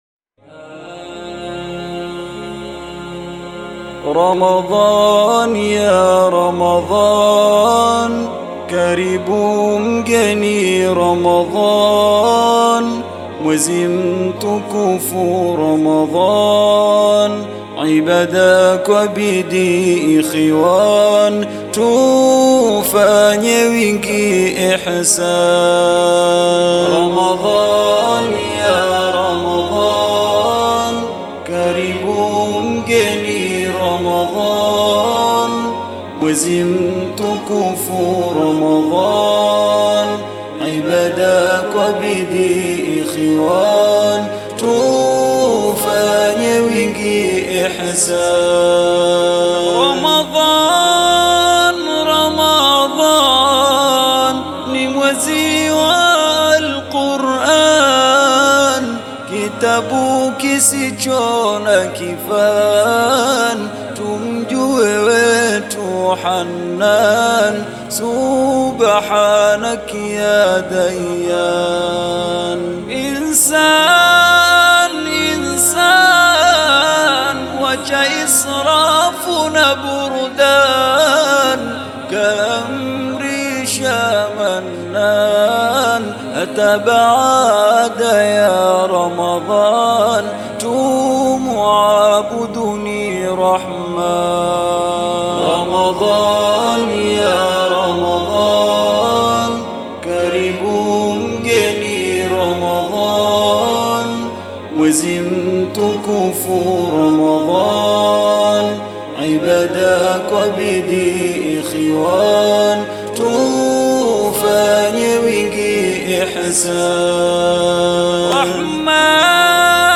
KASWIDA